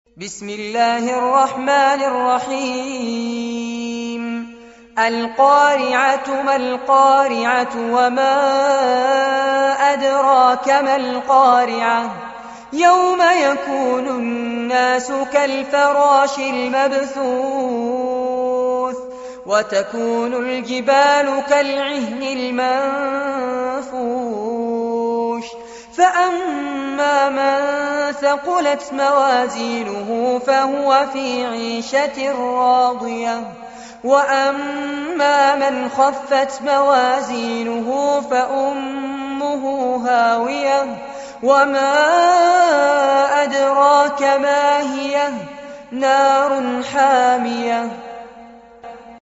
عنوان المادة سورة القارعة- المصحف المرتل كاملاً لفضيلة الشيخ فارس عباد جودة عالية